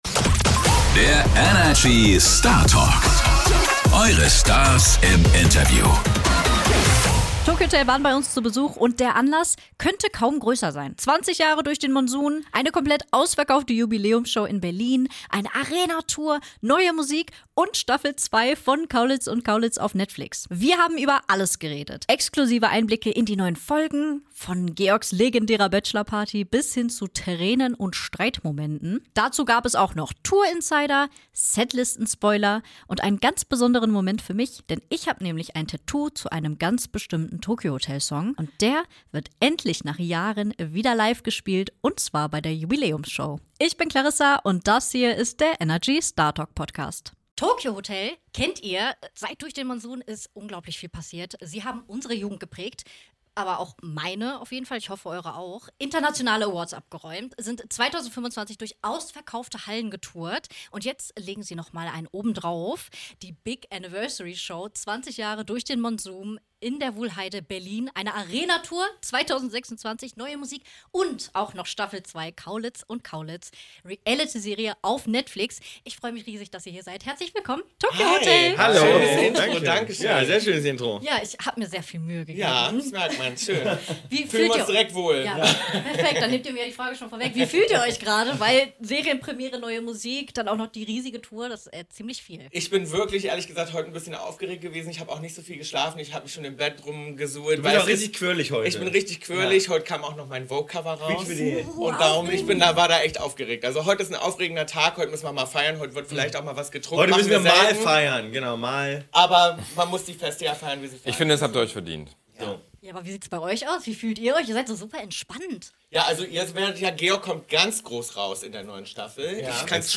In dieser Folge sprechen wir mit niemand Geringerem als Tokio Hotel. Im Fokus steht die neue Staffel ihrer Netflix Serie "Kaulitz & Kaulitz", die die Band über Monate hinweg begleitet. Bill, Tom, Georg und Gustav gewähren Einblicke in ihr Leben abseits der Bühne.